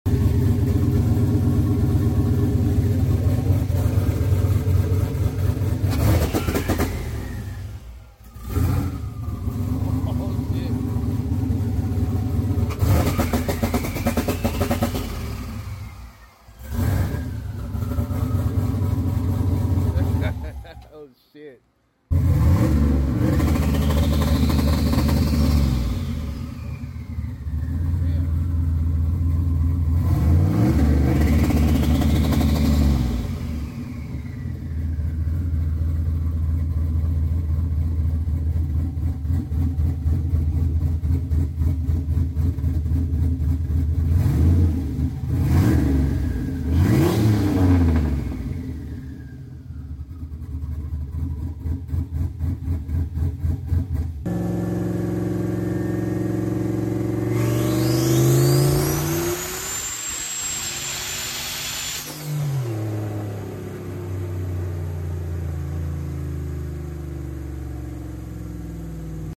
Turbo Noises!